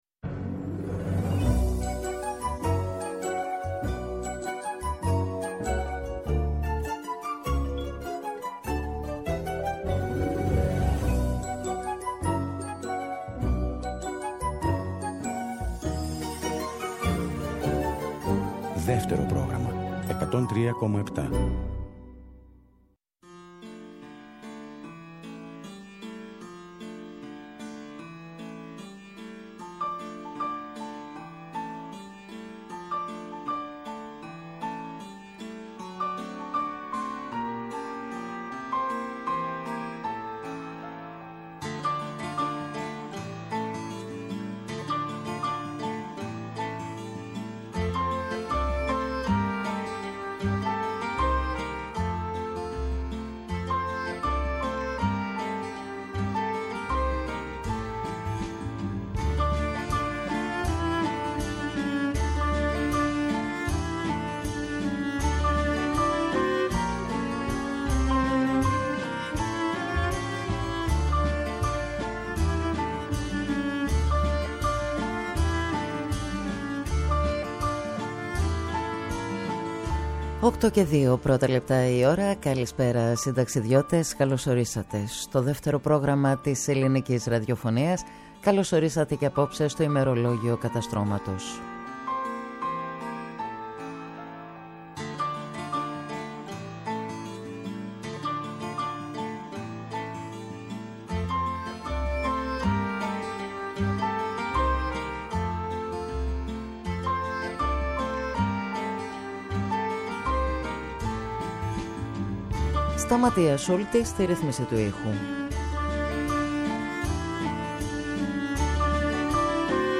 Συνταξιδιώτες οι ακροατές, ούριος άνεμος η μουσική και τα τραγούδια.